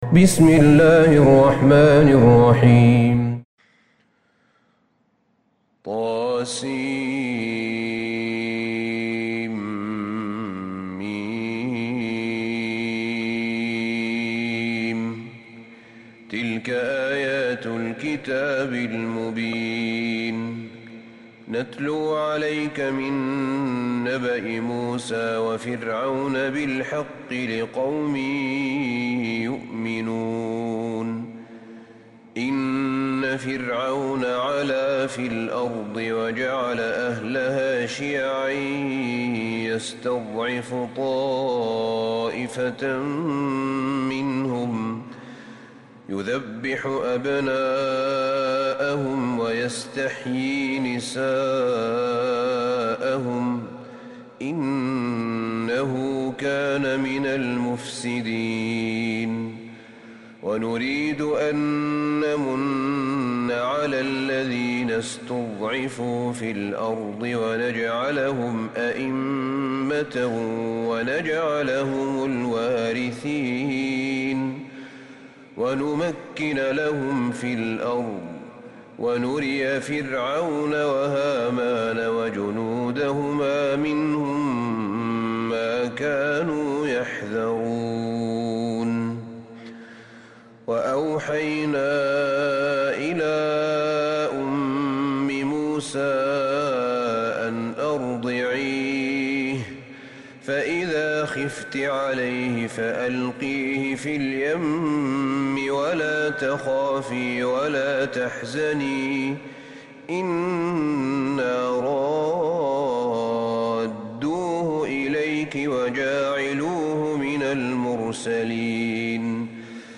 سورة القصص Surat Al-Qasas > مصحف الشيخ أحمد بن طالب بن حميد من الحرم النبوي > المصحف - تلاوات الحرمين